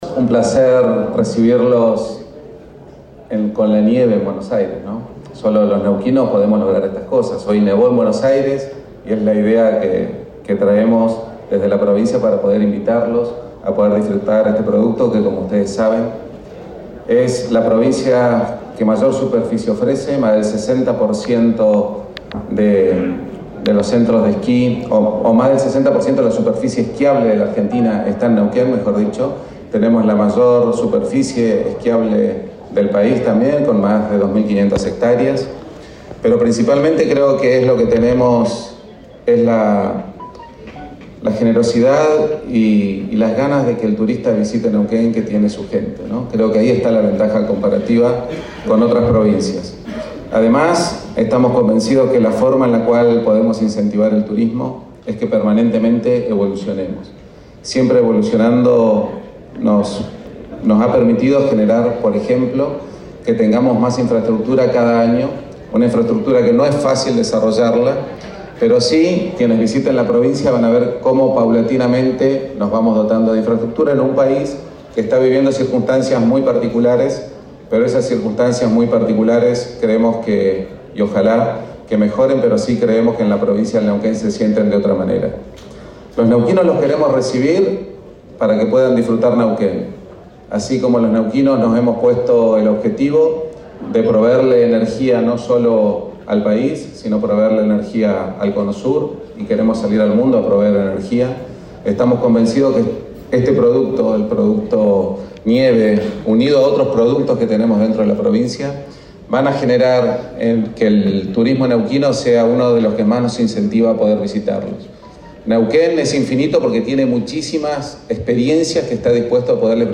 Rolando Figueroa, Gobernador del Neuquén.